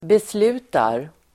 Uttal: [besl'u:tar]
beslutar.mp3